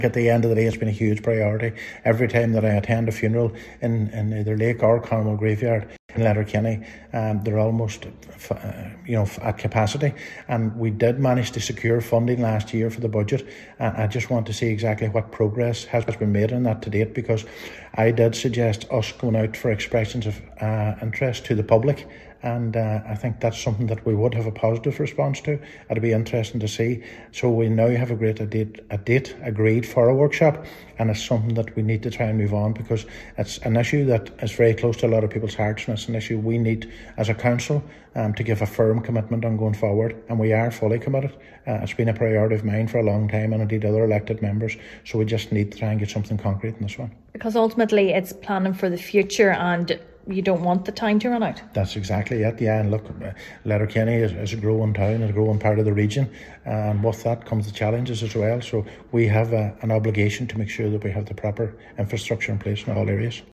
Councillor Brogan says, the pressure must remain on to plan for the future: